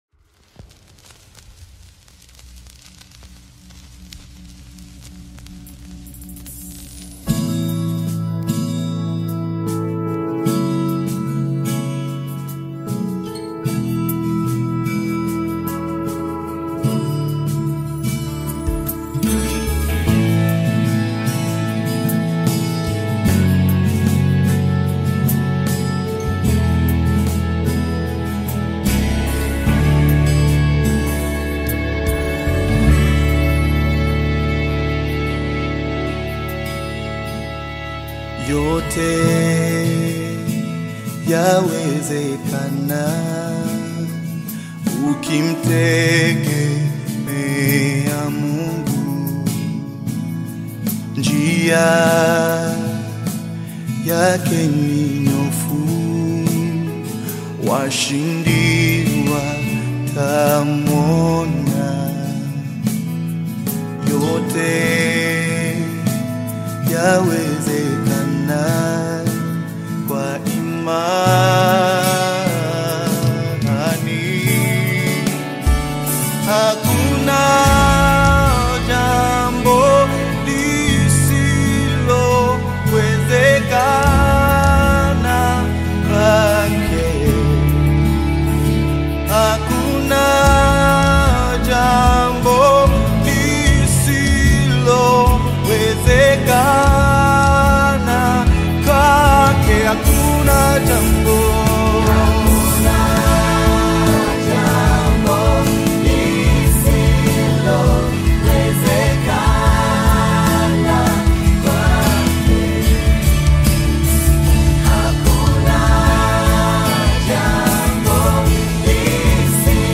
GOSPEL AUDIOS